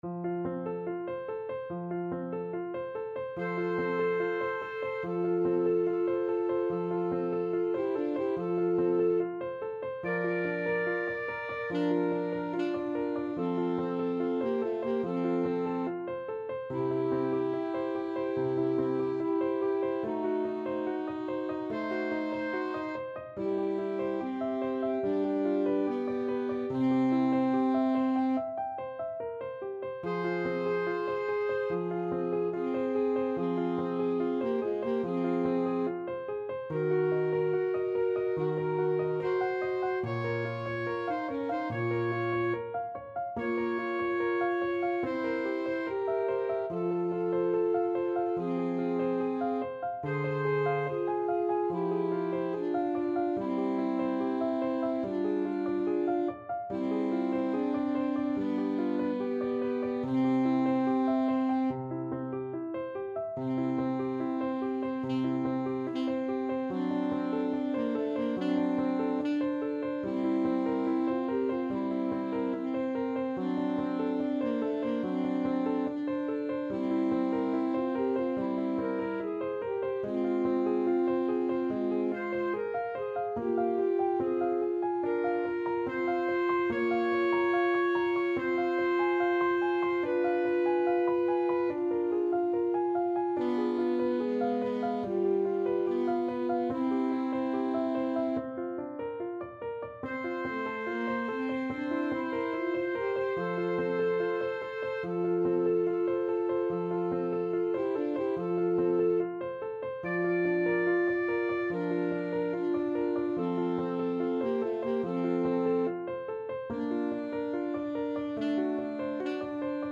Andante =72
Classical (View more Classical Alto Saxophone Duet Music)